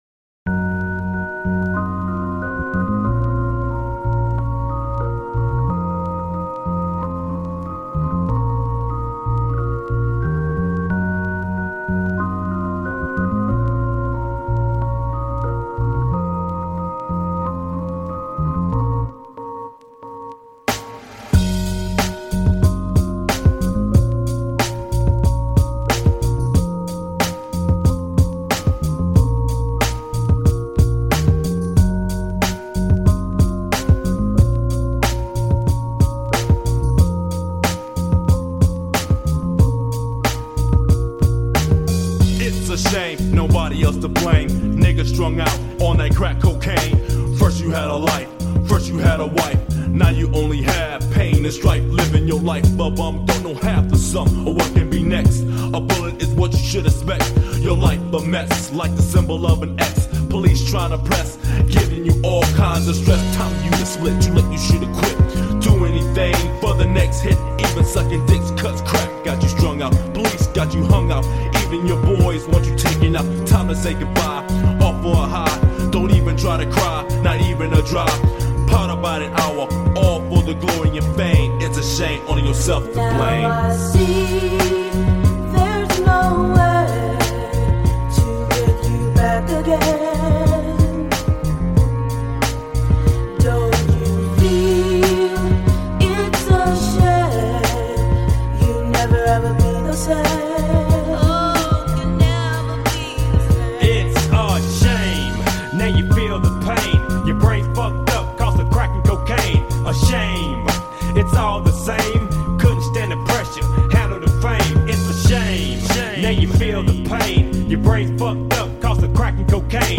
Жанр: Hip-Hop